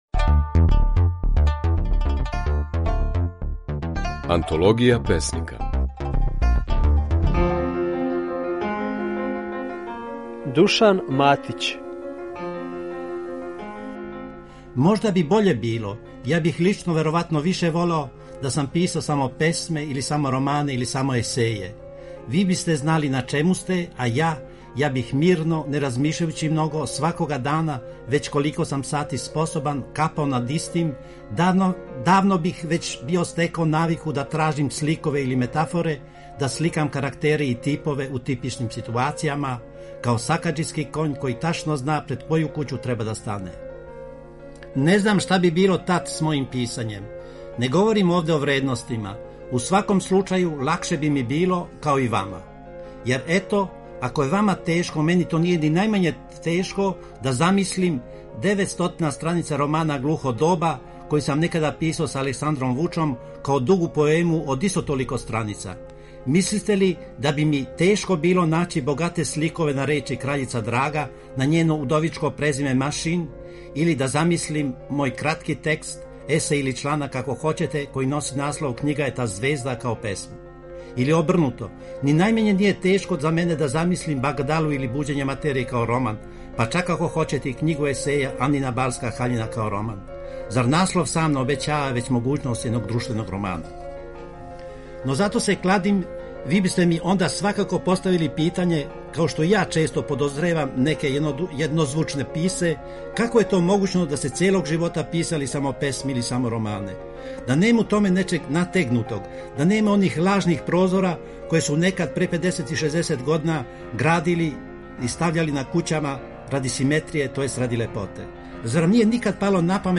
Можете чути како је своје стихове говорио песник и мислилац Душан Матић (Ћуприја, 1898 – Београд, 1980)
Емитујемо снимке на којима своје стихове говоре наши познати песници